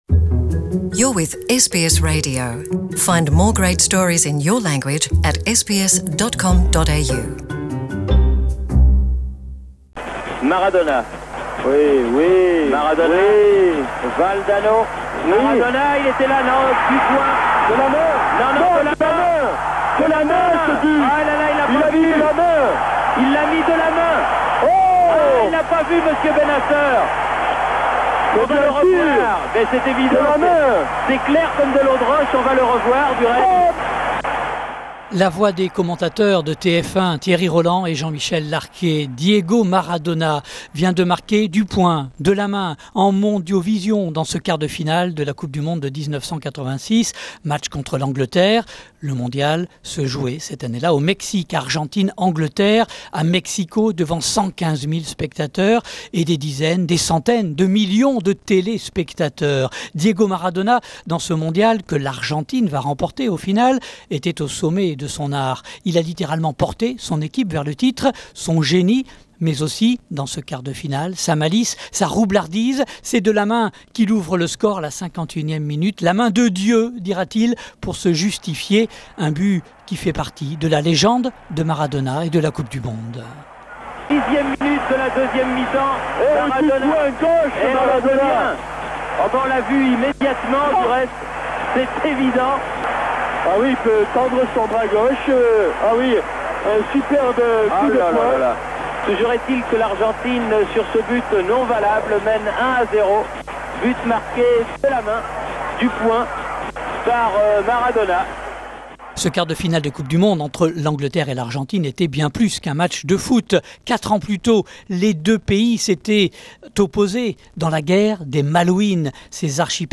C'est arrivé le 22 juin 1986 : Les deux buts du footballeur argentin Diego Maradona contre l'Angleterre. Retour avec les archives sonores de l'Institut National de l'Audiovisuel.